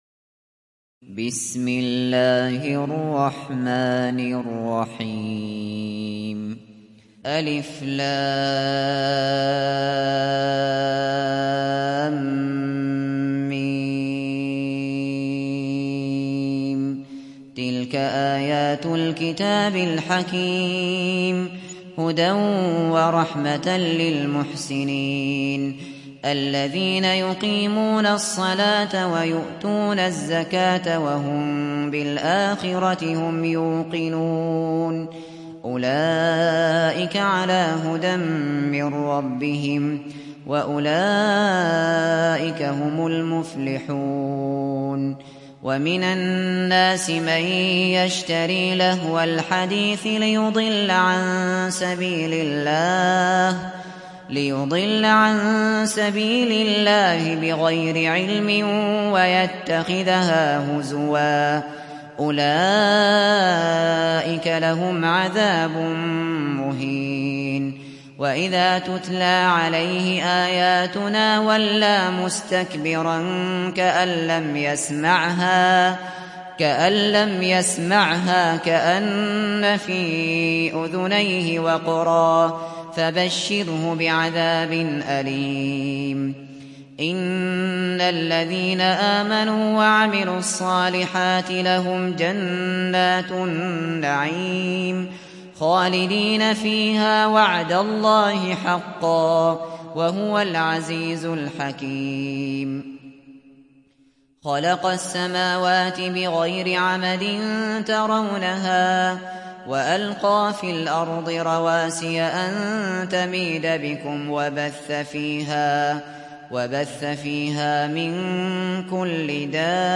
Surat Luqman mp3 Download Abu Bakr Al Shatri (Riwayat Hafs)